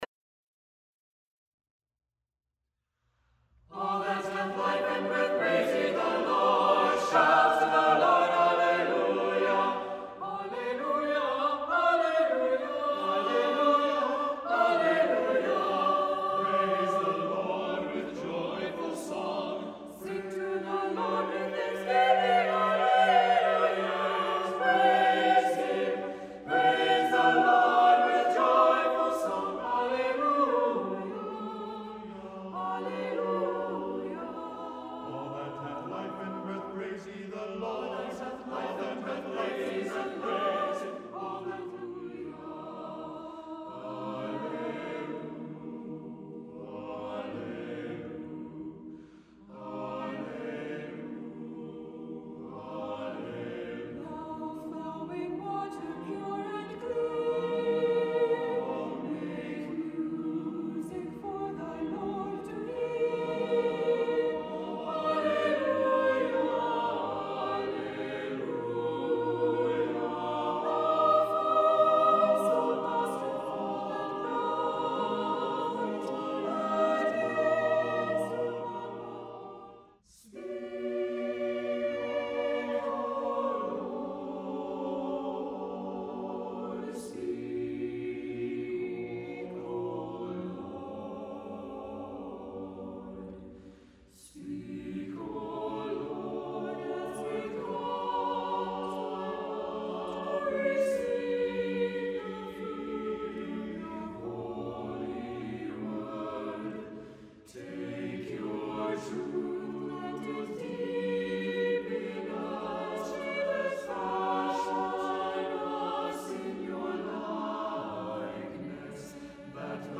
All songs acappella.
A good choral recording.